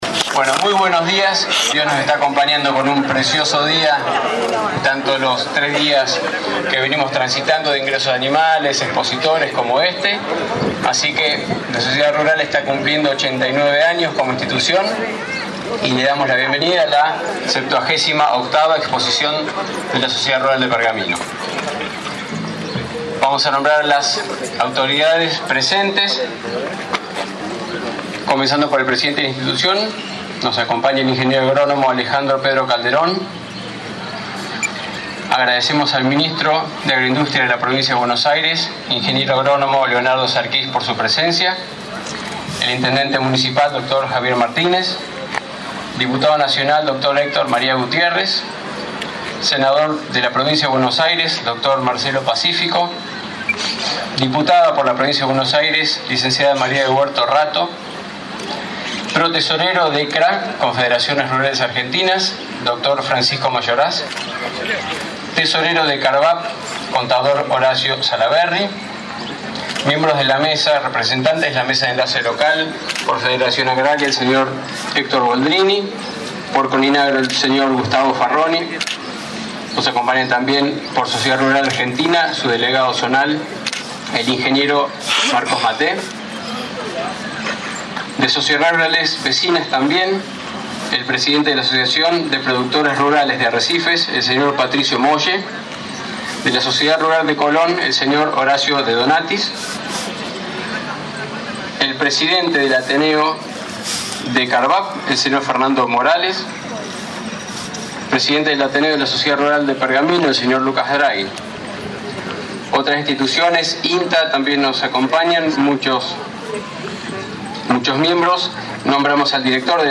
Ayer 10 de septiembre se realizó la apertura oficial en el patio principal de la expo.
Audio: Apertura protocolar.